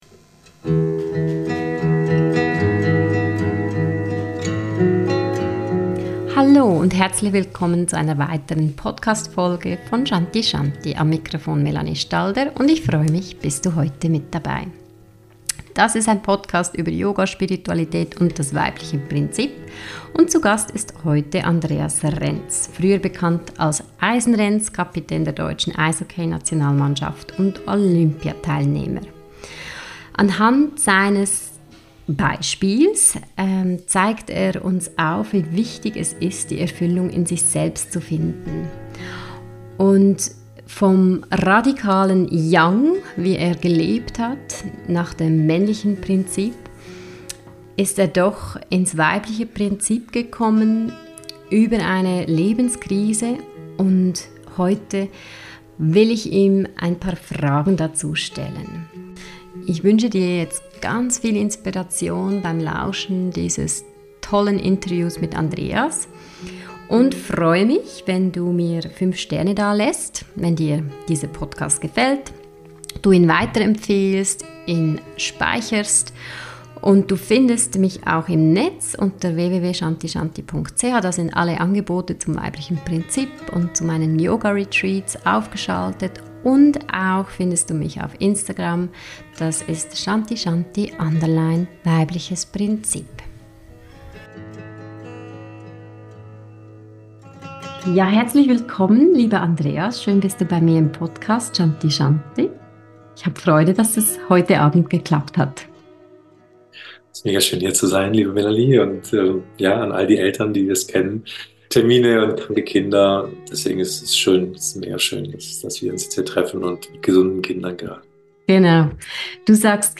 47: Dein härtester Gegner bist du selbst – Interview mit Andreas Renz